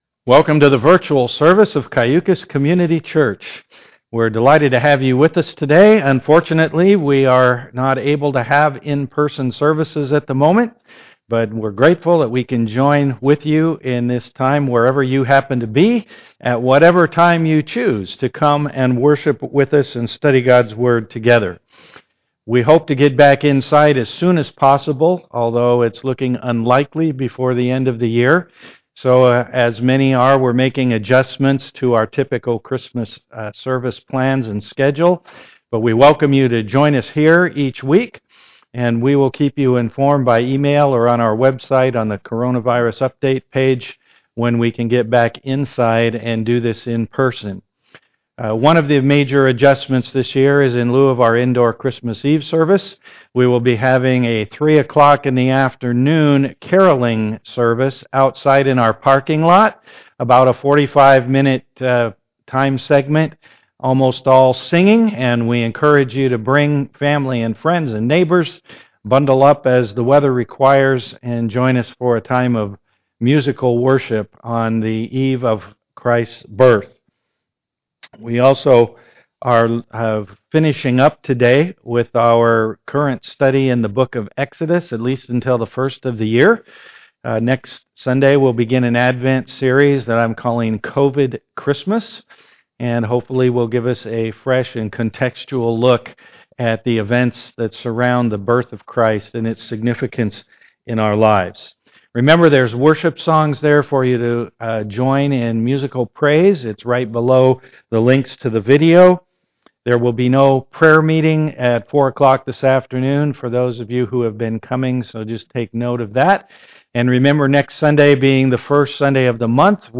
Series: Walking the (COVID-19) Wilderness With Moses Passage: Exodus 19 Service Type: am worship